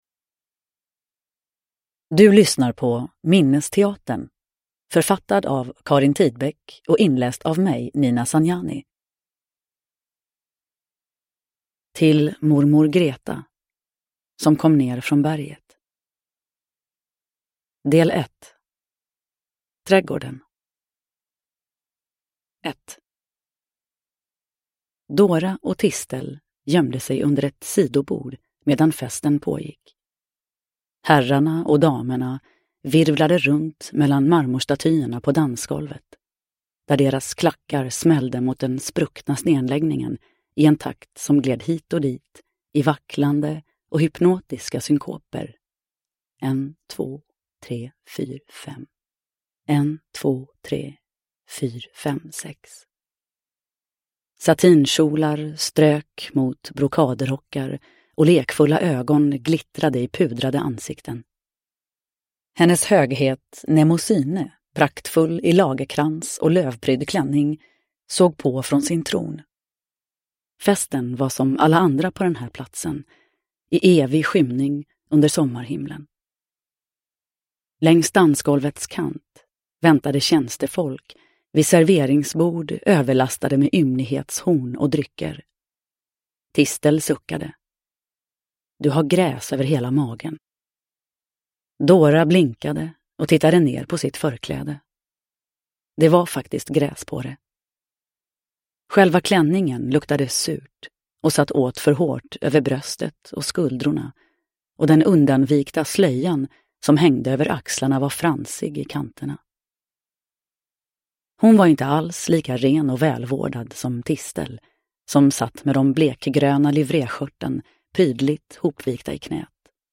Uppläsare: Nina Zanjani